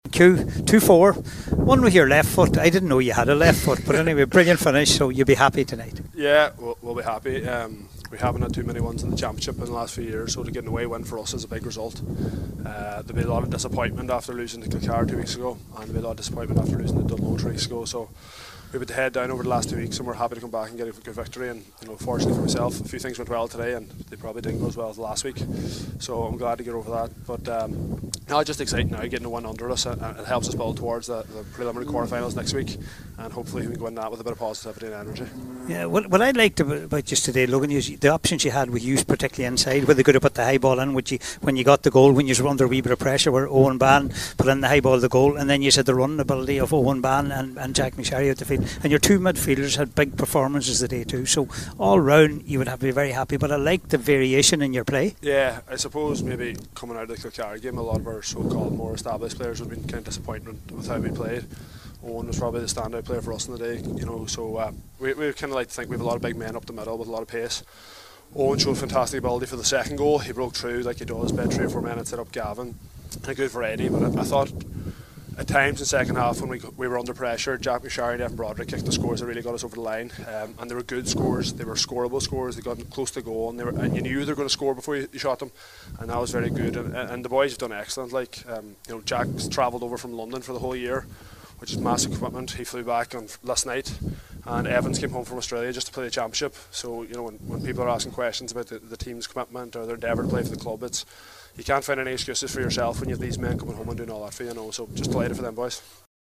Killybegs’ Hugh McFadden speaking with Martin McHugh after last night’s win
After the game, McFadden spoke to Martin McHugh and was delighted with the win…